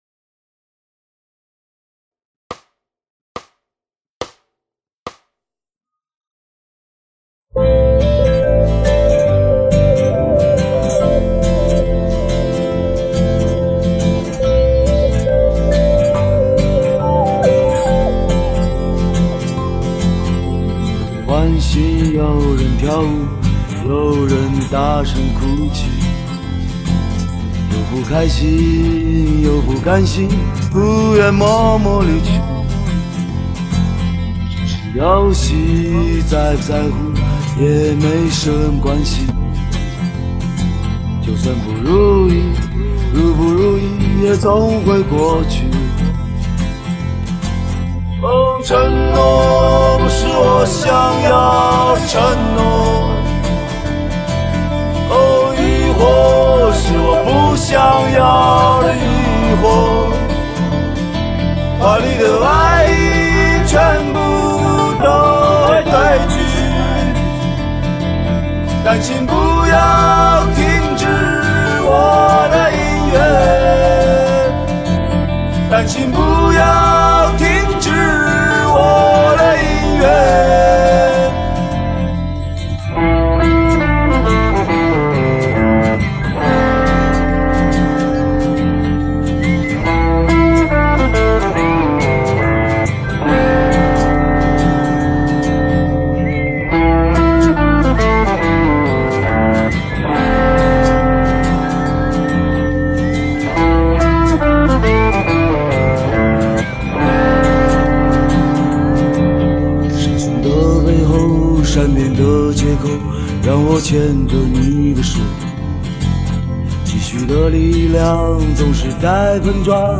速度每分钟70拍